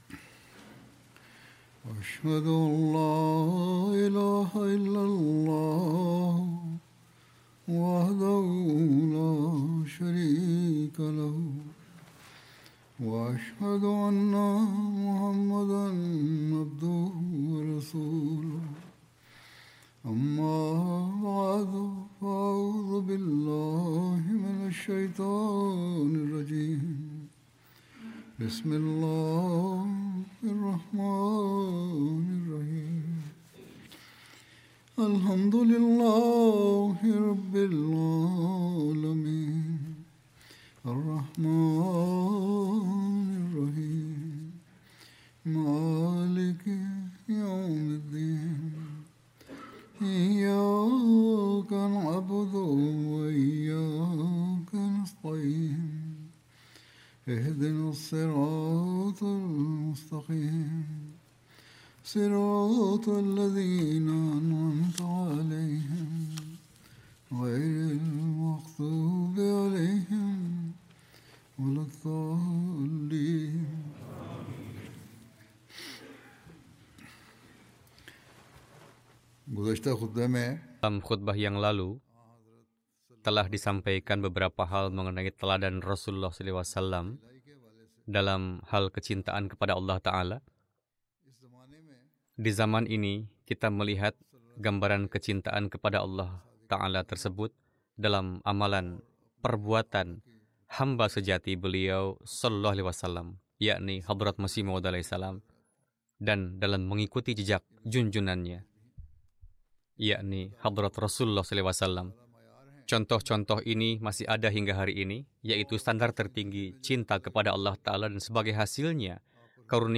Indonesian translation of Friday Sermon